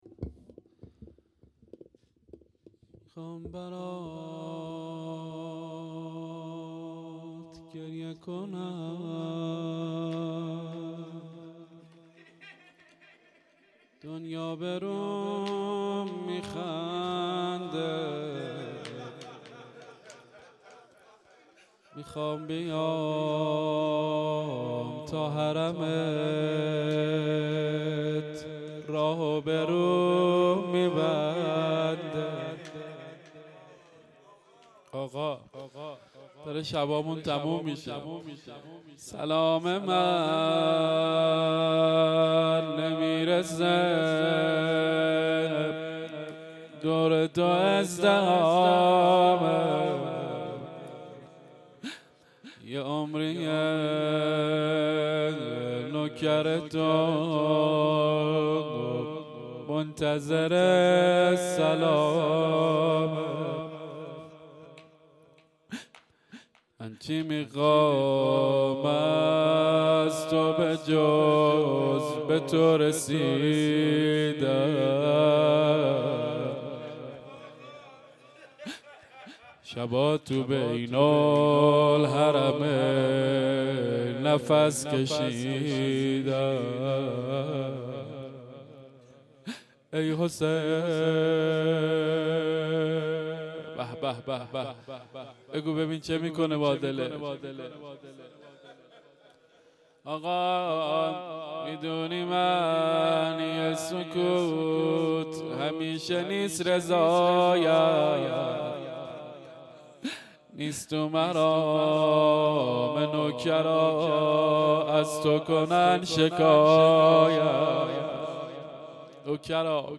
روضه